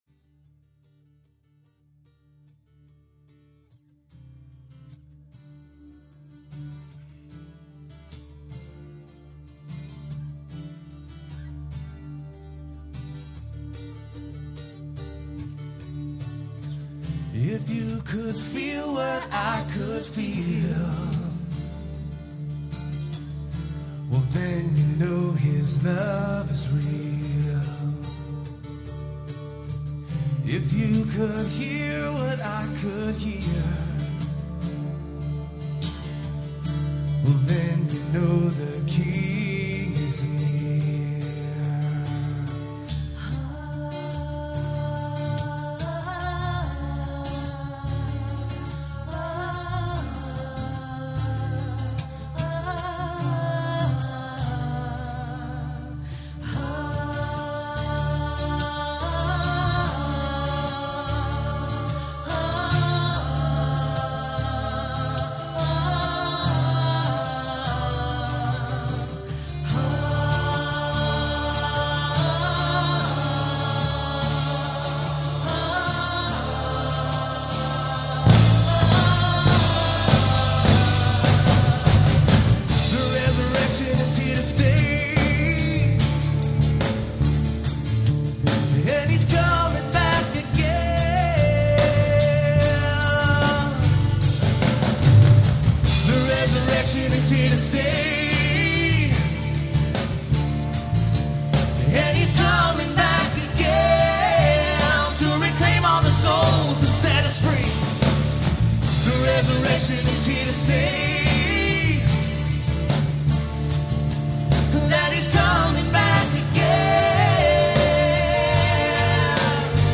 Performed live on Easter at Terra Nova - Troy on 3/23/08.